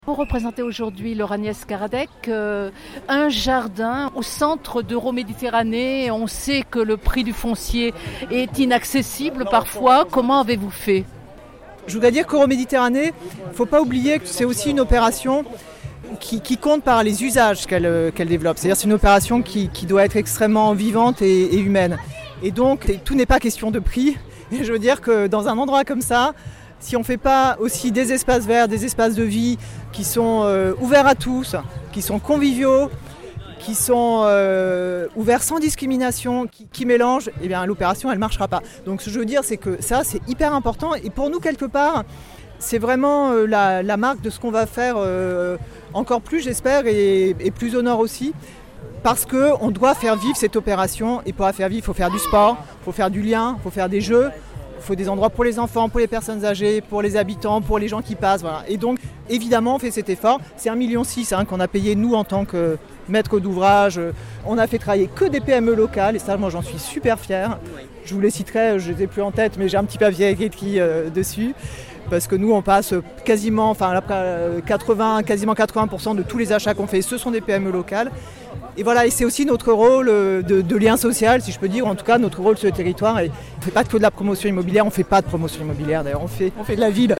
Au 14, rue de Ruffi dans le 3e arrondissement de Marseille, entre plants de fraises et barbe à papa, les habitants du quartier se pressaient pour cette fête de quartier organisée pour l’inauguration des Jardins de Ruffi à la fois jardin d’enfant et jardins partagés.